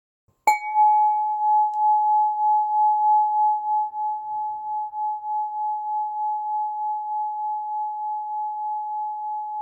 • la vibration est claire, brillante,
• les harmoniques s’installent naturellement dans l’espace,
Sa sonorité courte et précise en fait un outil idéal pour :
• Matériau : aluminium acoustique haute qualité (résonance stable et précise)
• Fréquence : Accordé précisément à la fréquence 852 Hz pour une expérience sonore pure et immersive.
Cette démonstration sonore vous donne néanmoins un aperçu de la clarté et de la puissance de l'Harmoniseur, vous invitant à découvrir l'expérience sensorielle complète par vous-même.
fréquence-852-Hz.mp3